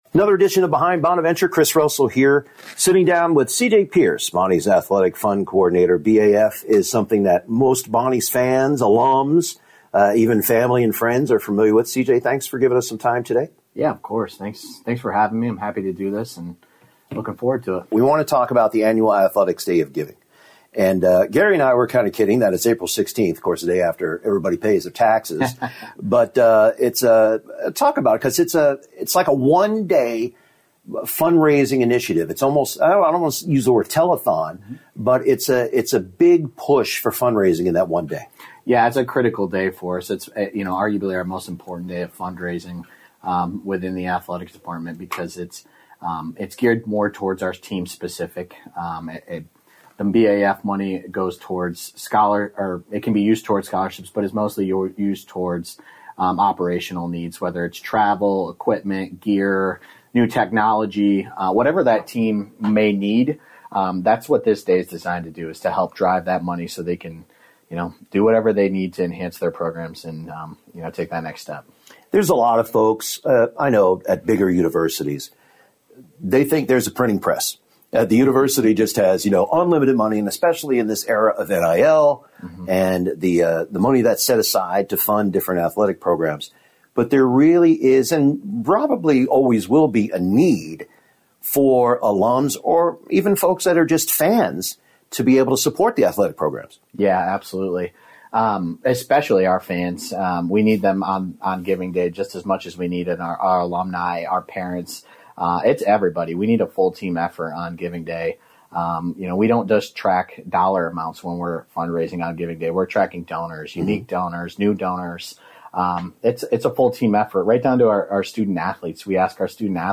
a discussion of the BAF